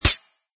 metal.mp3